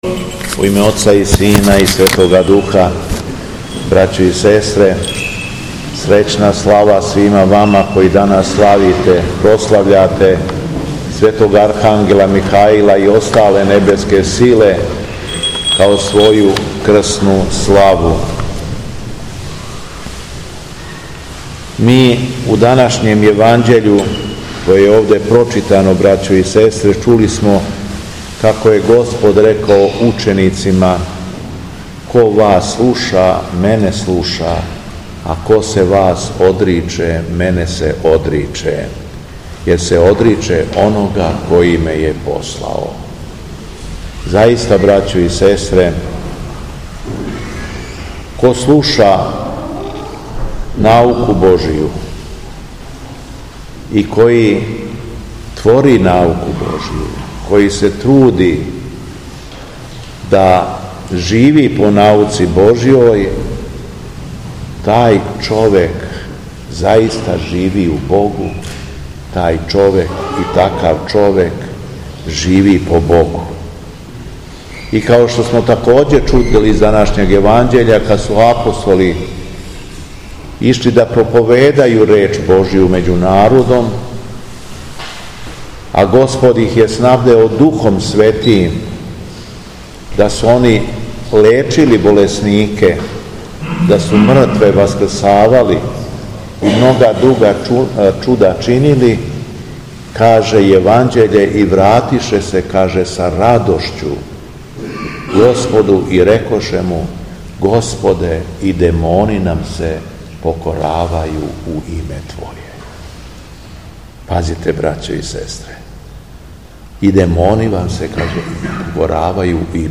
Беседа Његовог Преосвештенства Епископа шумадијског г. Јована
Након читања Светога Јеванђеља, Епископ Јован је окупљеним верницима честитао њихову крсну славу и поучио их својом беседом о Светим анђелима Божијим: